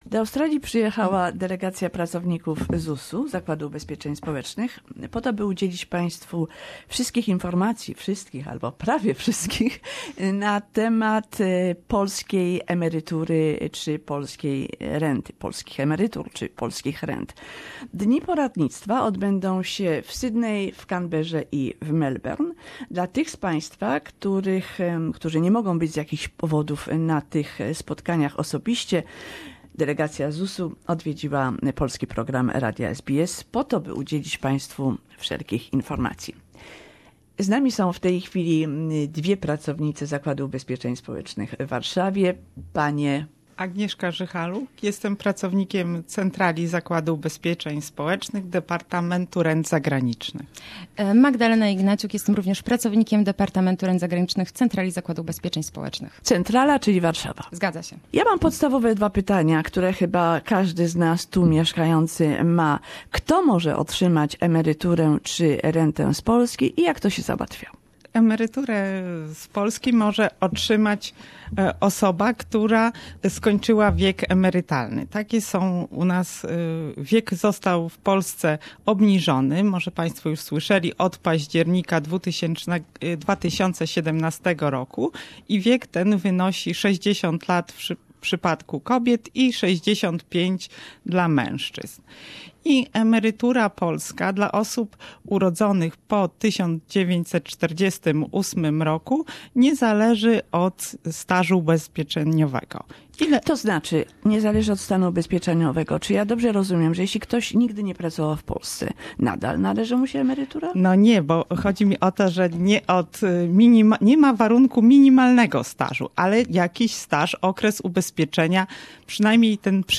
More information on social insurance benefits in the interview.
Specjaliści ZUS (Zakładu Ubezpieczeń Społecznych) w radiu SBS, Sydney.